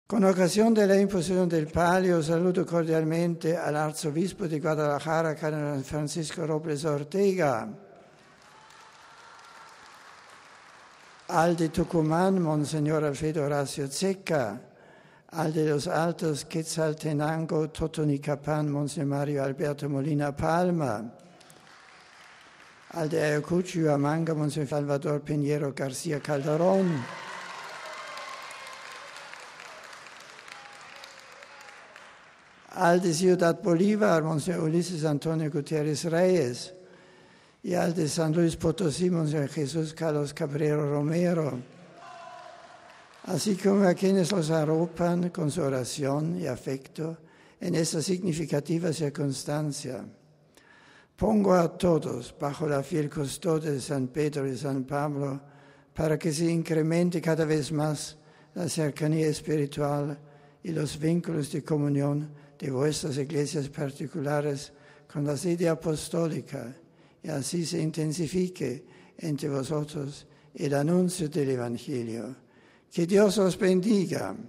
Esta mañana en el Aula Pablo VI, haciendo hincapié en «la universalidad de la Iglesia, llamada a hacer conocer a Cristo y a anunciar el Evangelio en todos los continentes y lenguas», el Papa fue saludando en italiano, francés, inglés, alemán, español, portugués y polaco. Escuchemos las palabras del Santo Padre a los seis Metropolitanos de habla hispana, a los fieles que los acompañaban y a sus respectivas Iglesias: RealAudio